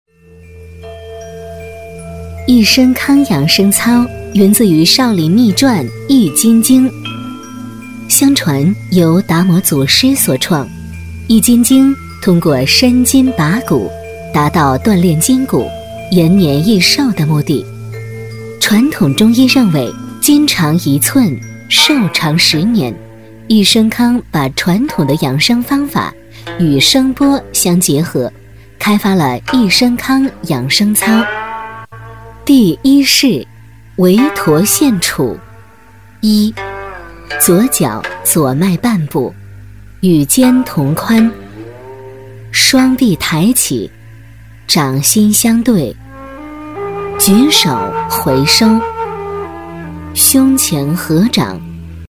女150-语音导游 易生康养生操（缓缓道来）
女150-中英双语 成熟知性
女150-语音导游 易生康养生操（缓缓道来）.mp3